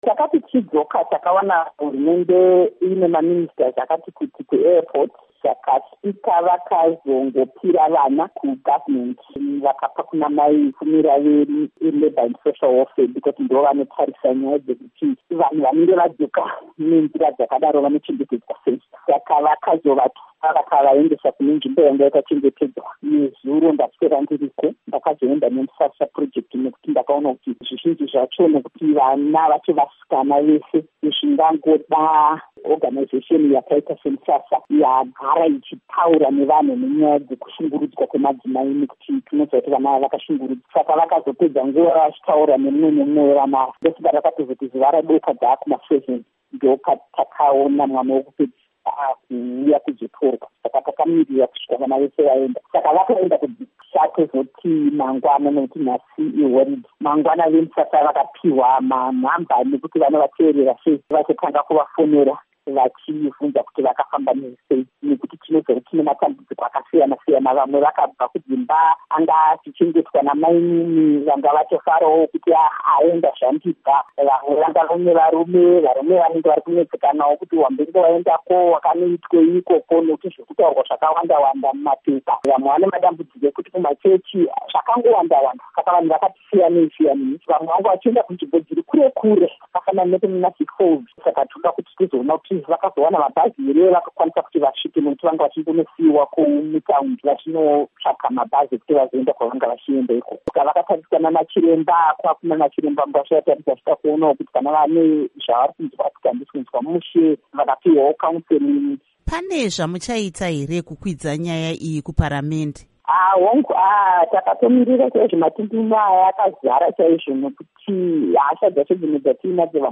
Hurukuro NaAmai Priscilla Misihairambwi-Mushonga